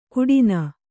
♪ kuḍina